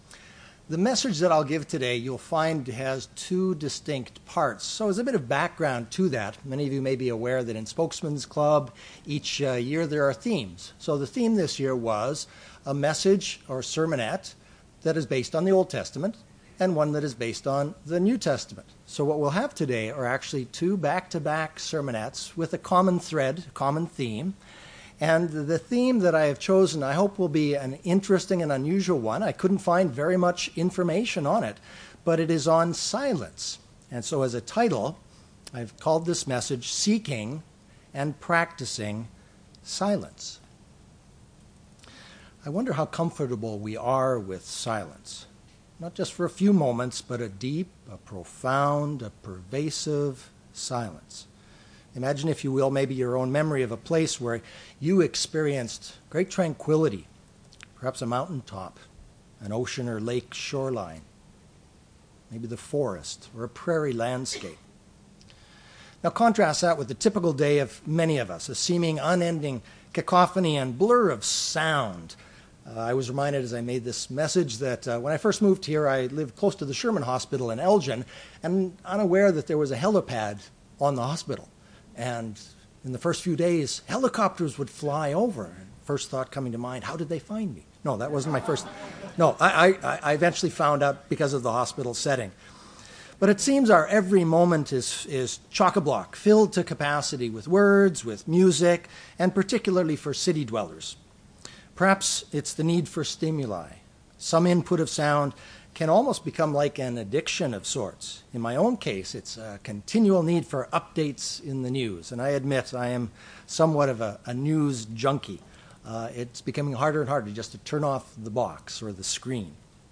Given in Chicago, IL
We may also learn from Christ's example in the NT that practicing silence develops the spiritual fruit of self-control. silence UCG Sermon Studying the bible?